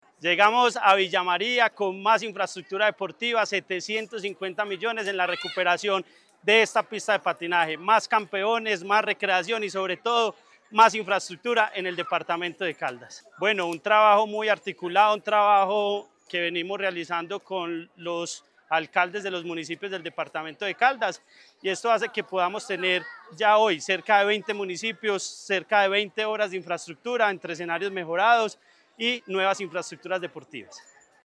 Andrés Duque Osorio, secretario de Deporte, Recreación y Actividad Física de Caldas.